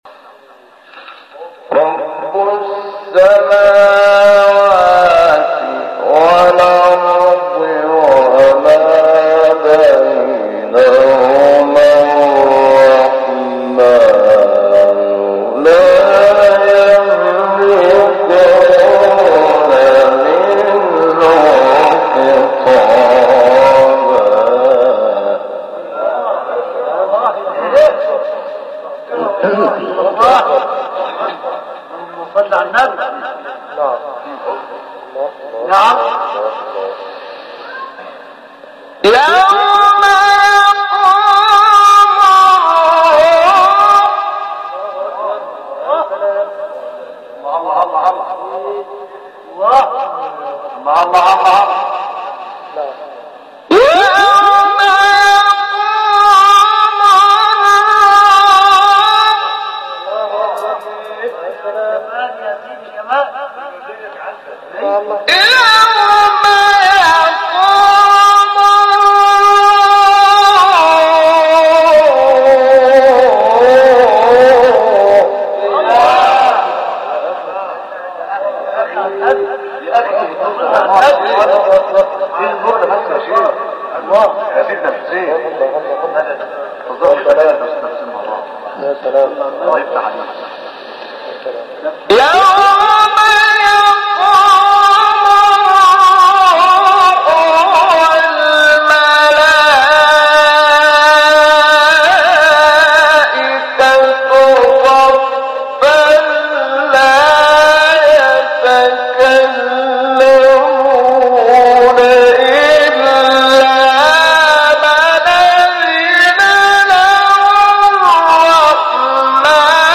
مقام حجاز نباء شحات محمد انور | نغمات قرآن
مقام : حجاز